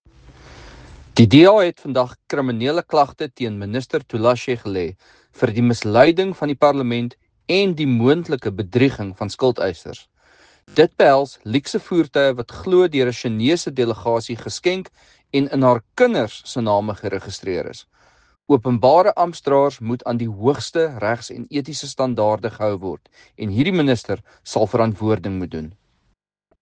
Afrikaans soundbite by Jan de Villiers MP.